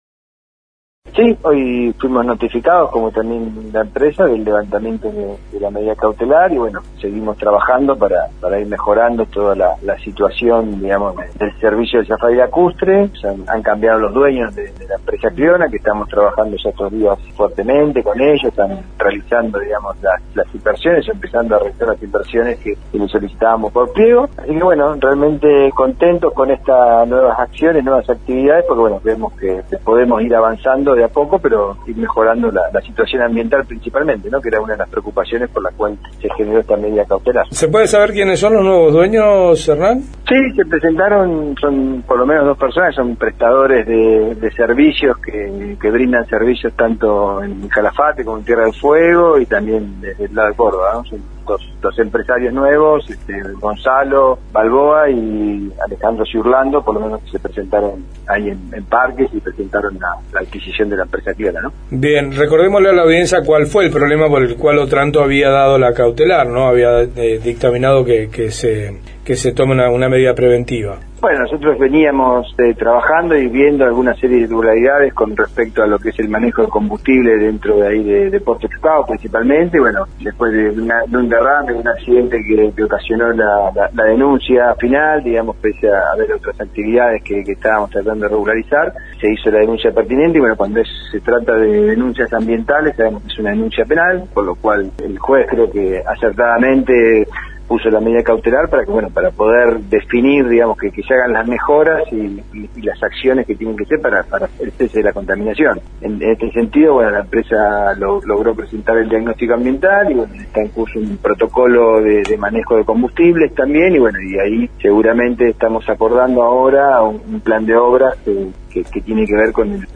En diálogo con el programa «Trabajo de Hormiga» que se emite por Radio Nacional Esquel, informó que fueron notificados del levantamiento de la medida cautelar y que la empresa Cleona tiene nuevos dueños.